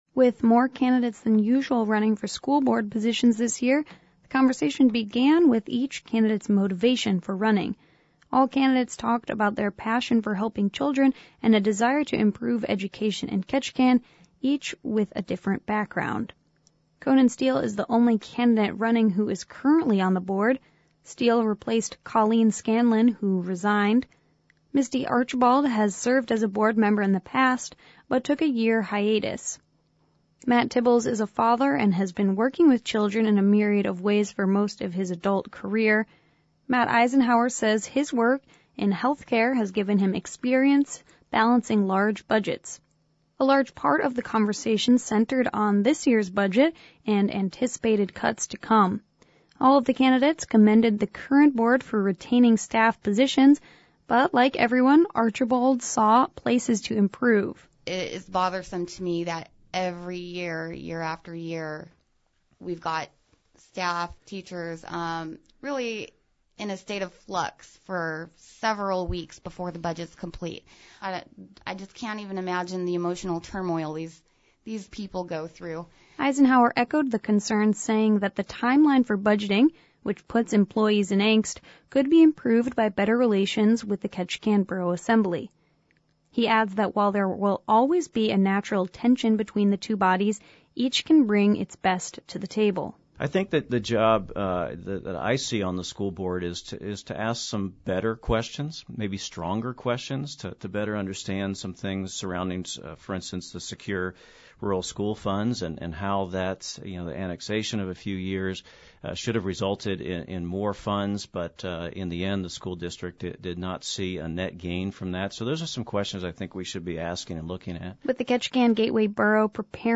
Four of the five candidates were able to attend a live forum on KRBD last week.